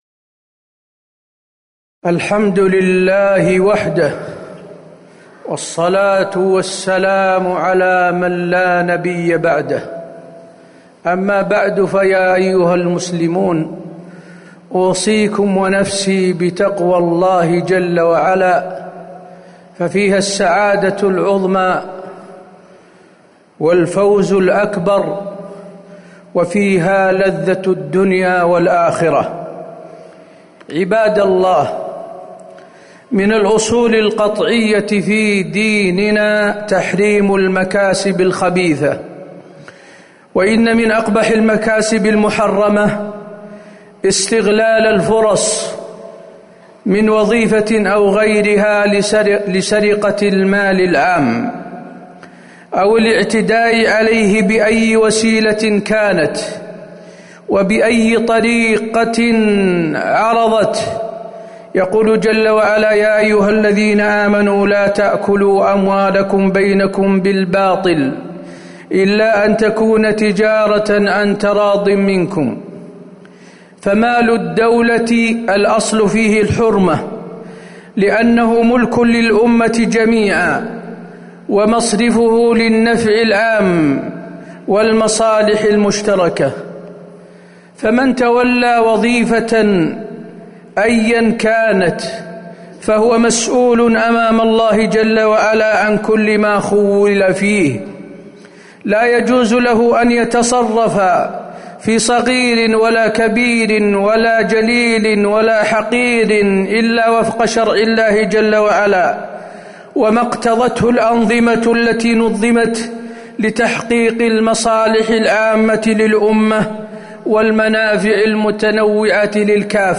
تاريخ النشر ٨ جمادى الأولى ١٤٤١ هـ المكان: المسجد النبوي الشيخ: فضيلة الشيخ د. حسين بن عبدالعزيز آل الشيخ فضيلة الشيخ د. حسين بن عبدالعزيز آل الشيخ المحافظة على المال العام